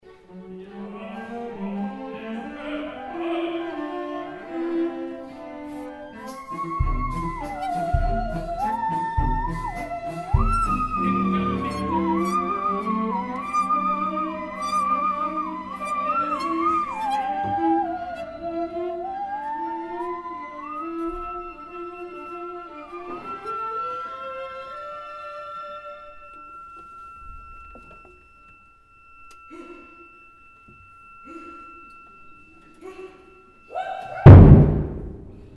Finale - last bars of the opera with Musical saw
This atmosphere can be expressed much more adequate with the more subtle and "lonely" sound of a Musical saw, which really ends "morendo" (listen to 3a).